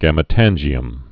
(gămĭ-tănjē-əm)